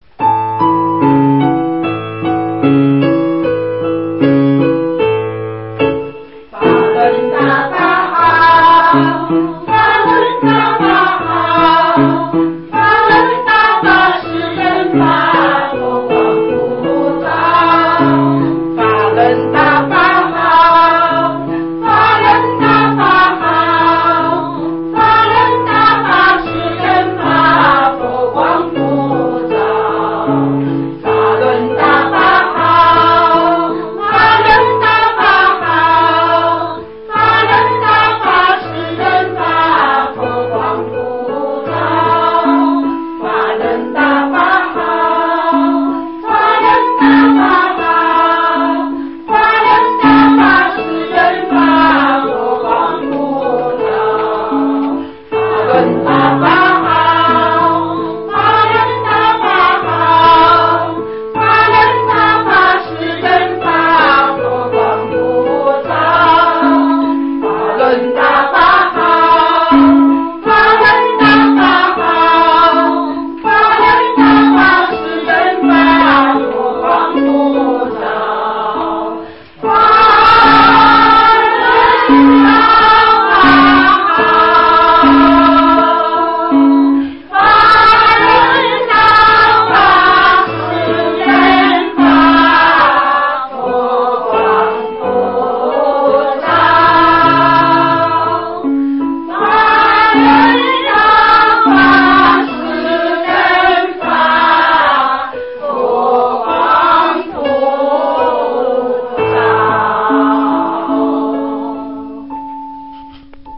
Đệ tử Đại Pháp tại một nhóm học Pháp tại Thành Đô, Tứ Xuyên gửi tặng Sư phụ bài hát!